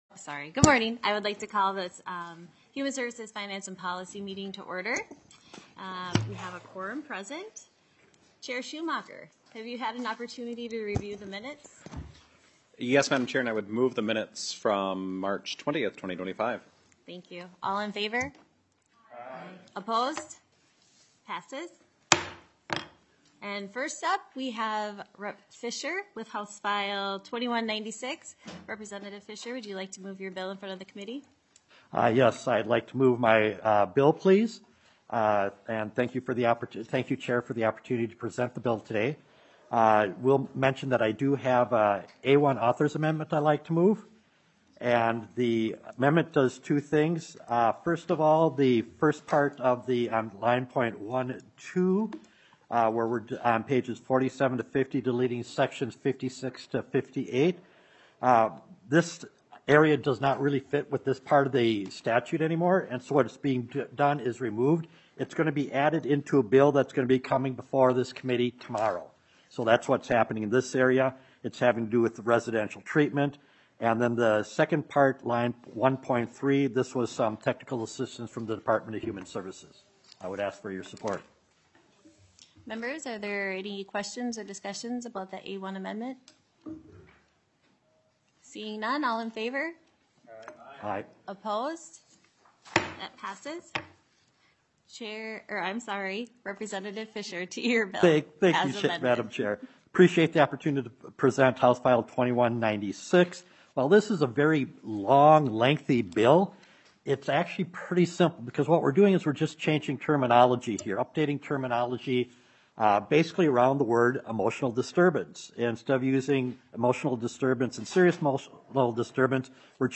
Human Services Finance and Policy NINETEENTH MEETING - Minnesota House of Representatives